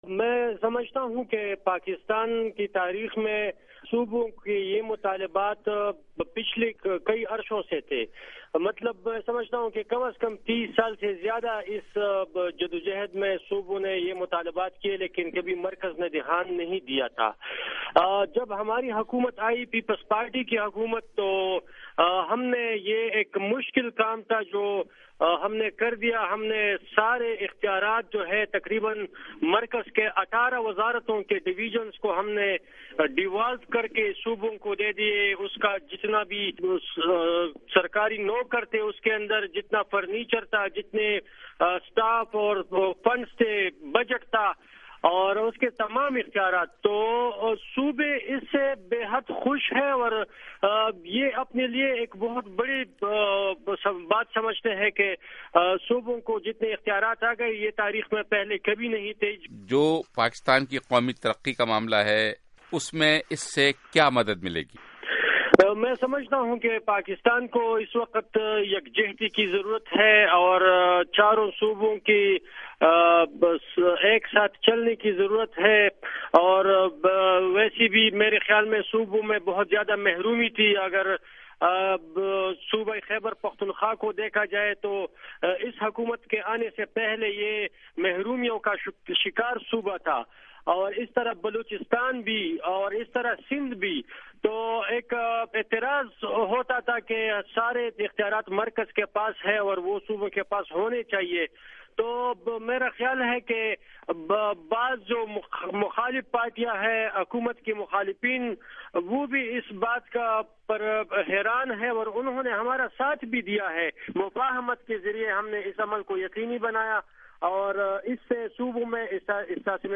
یومِ صوبائی خودمختاری کے حوالے سے اتوار کو’ وائس آف امریکہ‘ کے حالاتِ حاضرہ کے پروگرام میں گفتگو کرتے ہوئے اُنھوں نے کہا کہ سابقہ دہائیوں کے دوران صوبوں کی عام شکایت یہ رہی ہے کہ ’تمام اختیارات وفاق کے پاس مرکوز ہیں‘۔